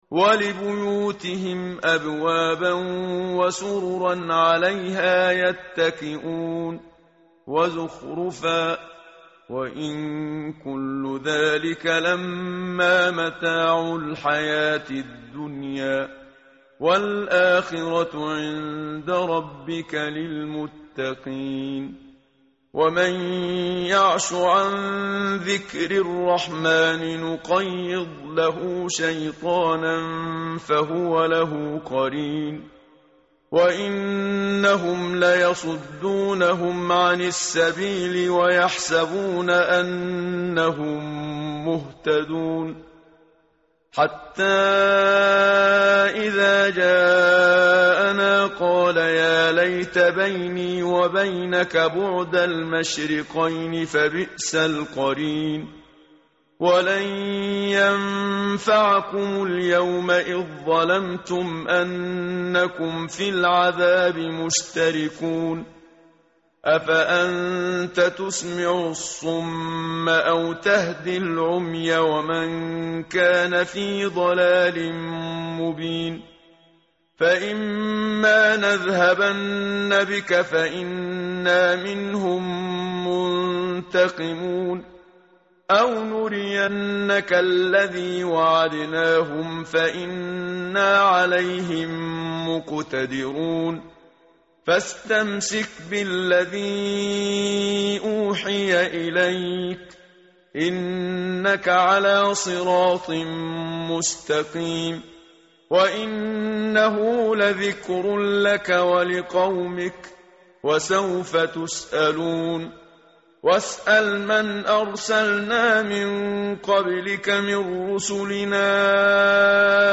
متن قرآن همراه باتلاوت قرآن و ترجمه
tartil_menshavi_page_492.mp3